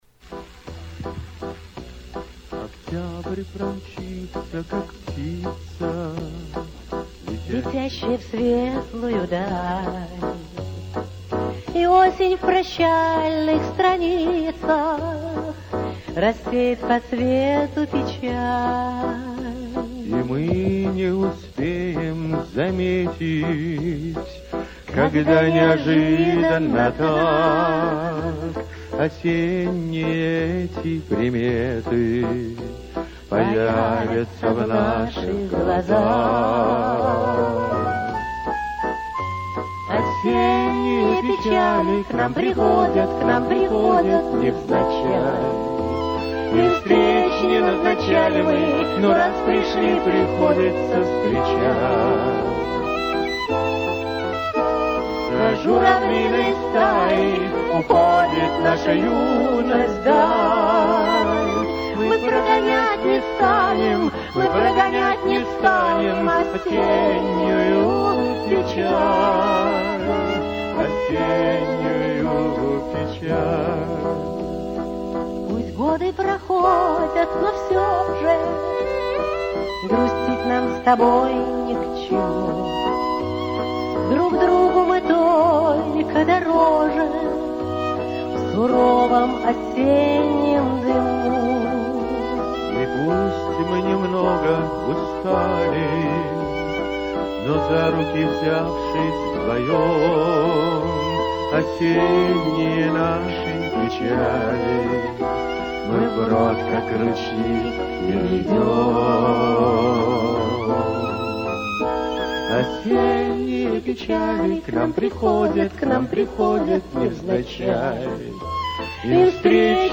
концерт